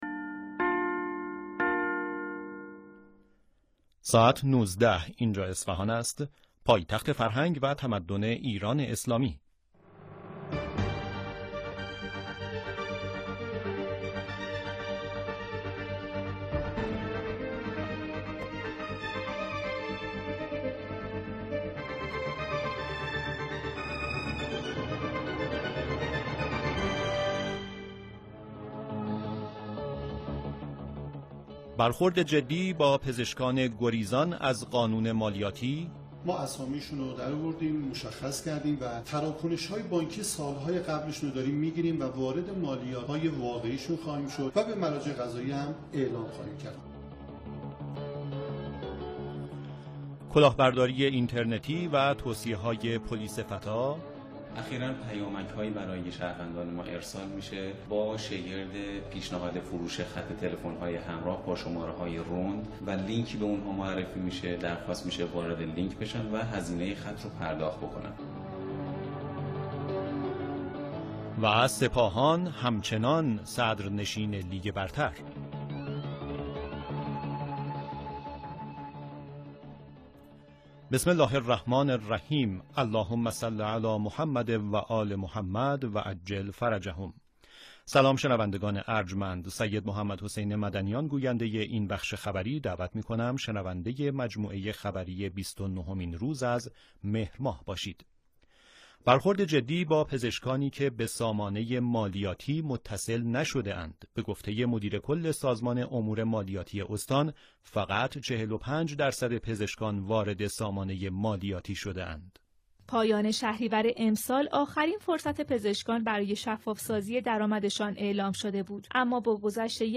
برخی از مهمترین رویدادها و گزارش های خبری امروز را در اخبار 19 صدای مرکز اصفهان بشنوید.